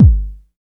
09.2 KICK.wav